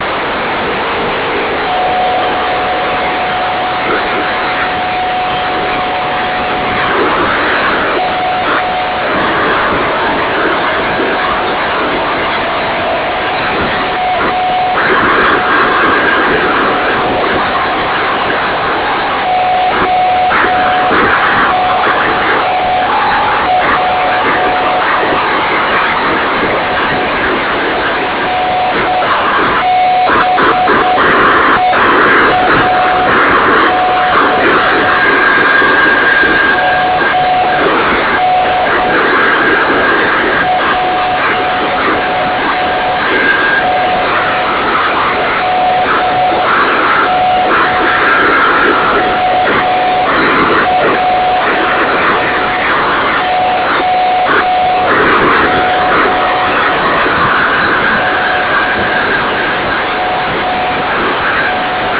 Rozkmit a jeho probl�my... Muzika ze st�edn�ch vln, ale p�esto je to n�dhern� blbnut�.
Jak je sly�et 10 mW v Plzni?